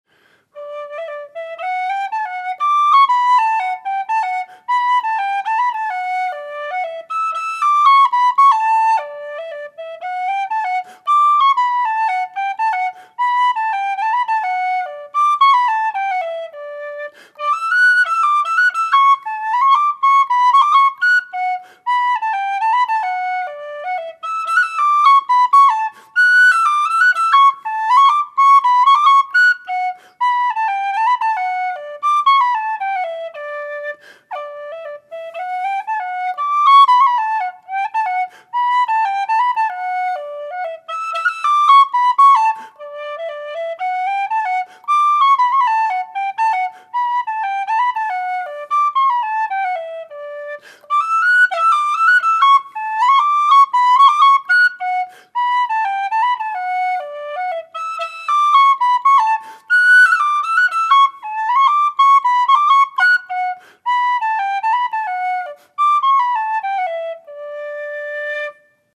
D Whistles
made out of thin-walled aluminium tubing with 13mm bore